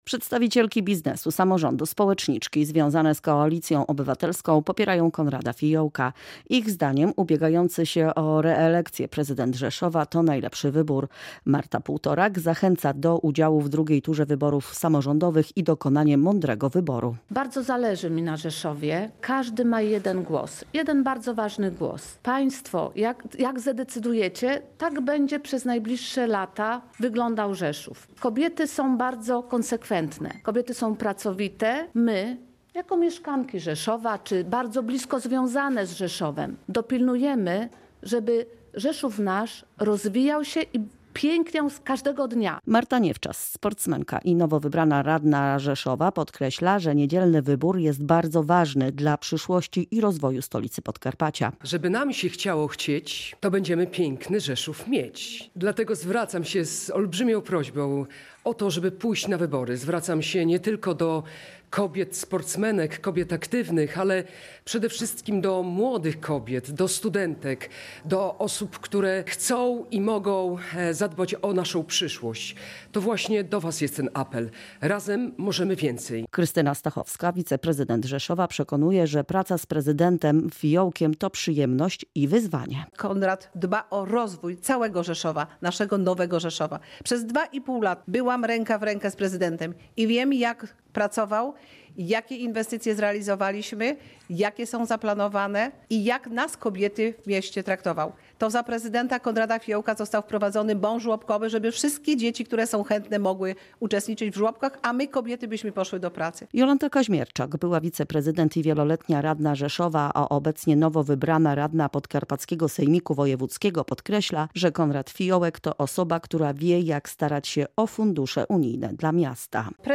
To przedstawicielki biznesu, samorządu i społeczniczki. Przekonywały, że ubiegający się reelekcję prezydent Rzeszowa jest gwarantem dalszego, prężnego rozwoju Rzeszowa.
Relacja